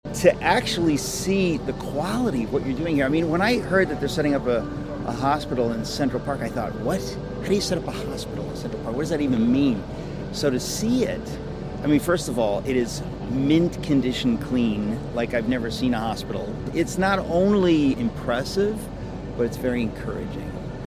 Listen to New York City resident, author, and talk radio show host Eric Metaxas express his wonder at what he saw when he went to visit the facility.